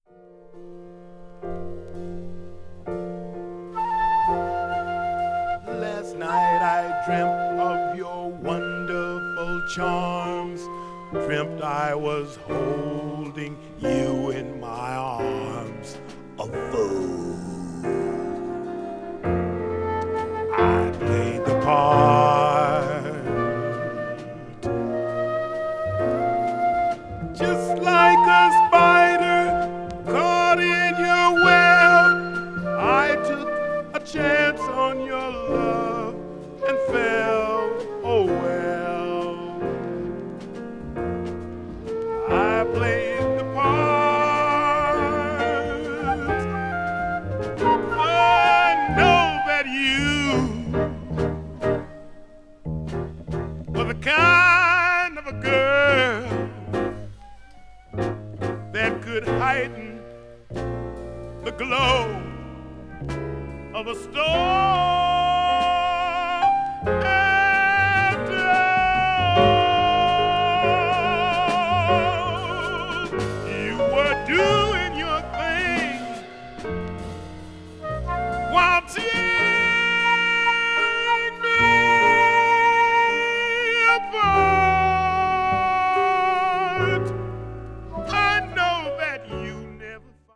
blues jazz   jazz vocal   post bop